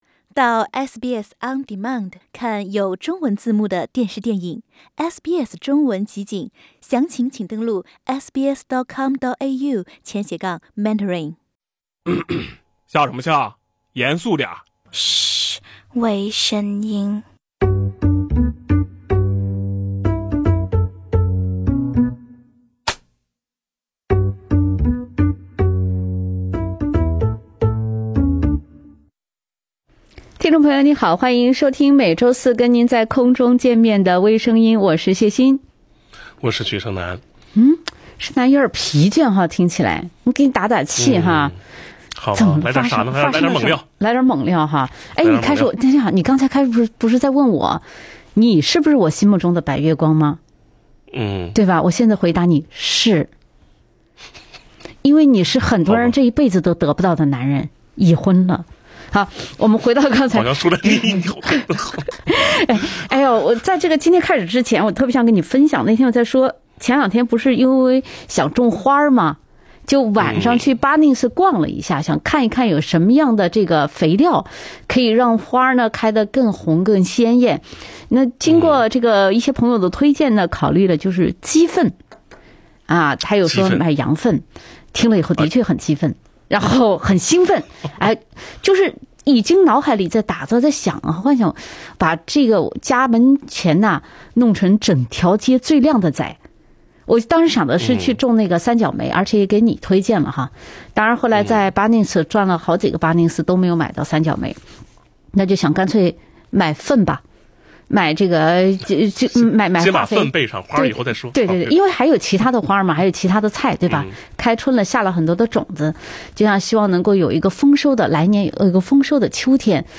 另类轻松的播报方式，深入浅出的辛辣点评，更劲爆的消息，更欢乐的笑点，敬请收听每周四上午8点30分播出的时政娱乐节目《微声音》。